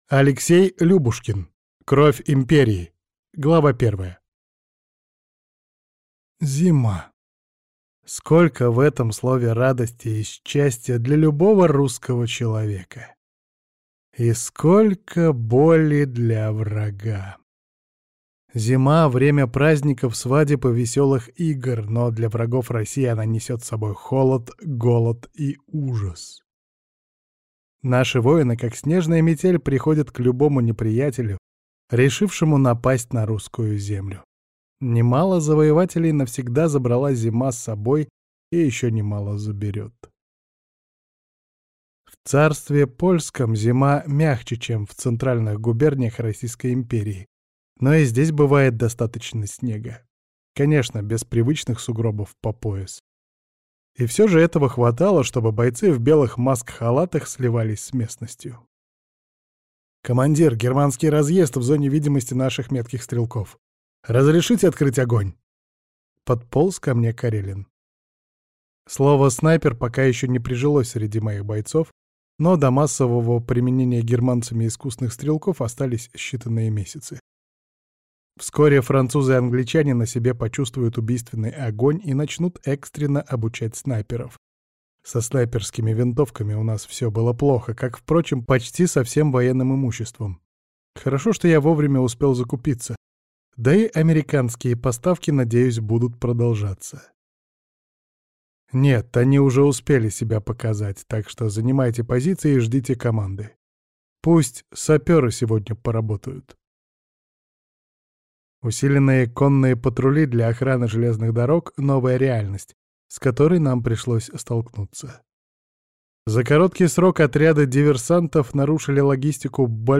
Шакьямуни (Будда). Его жизнь и религиозное учение (слушать аудиокнигу бесплатно) - автор К. М. Карягин